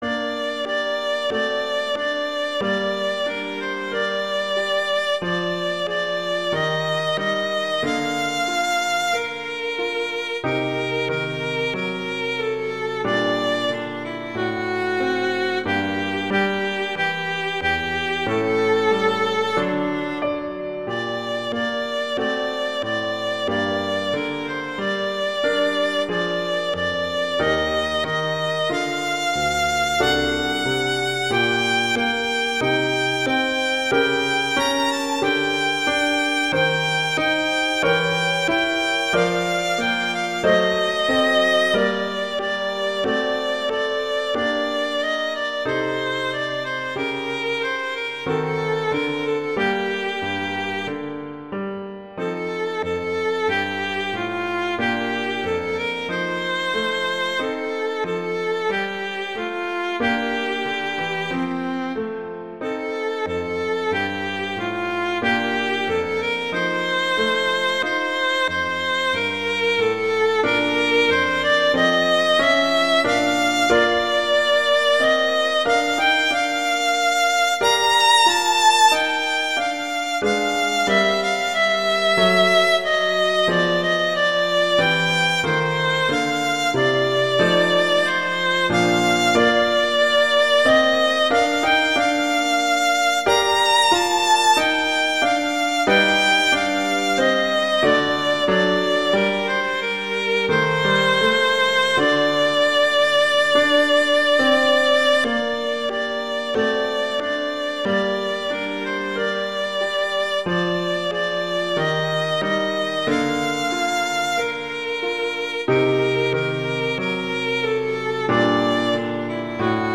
classical, children
G minor
♩=92 BPM